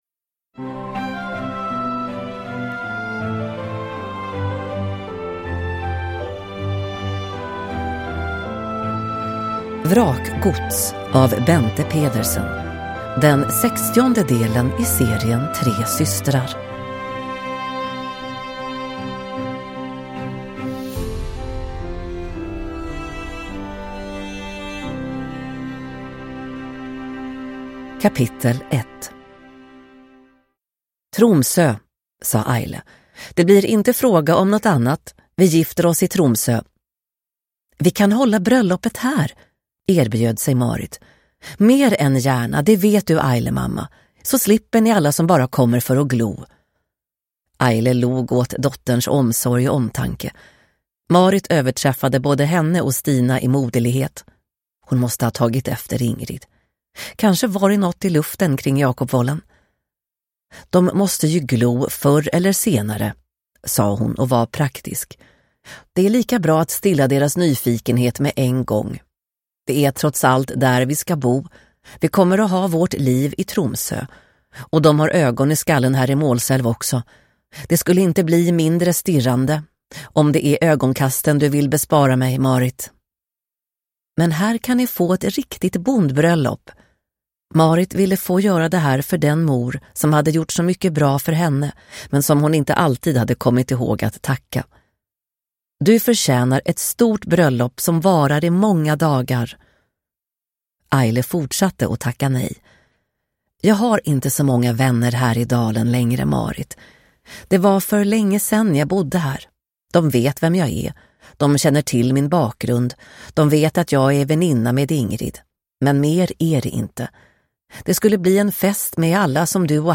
Vrakgods – Ljudbok